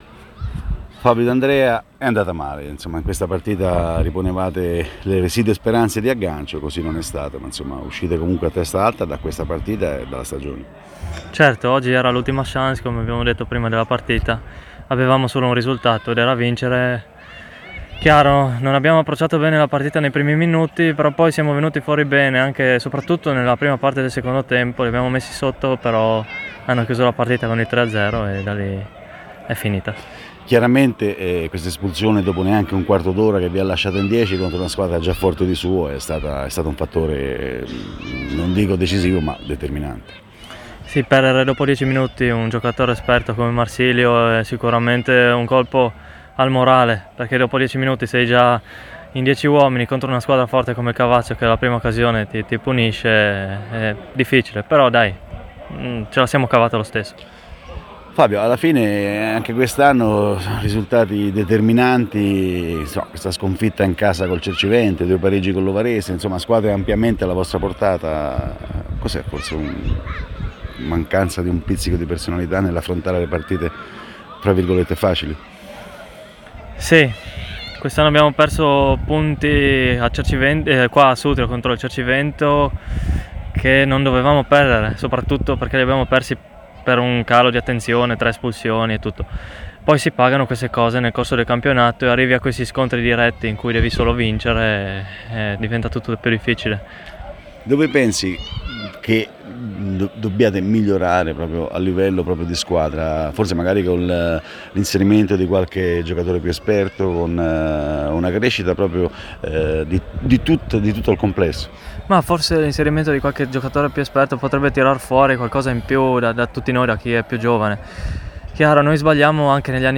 Proponiamo l’intervista realizzata al termine del big match Mobilieri-Cavazzo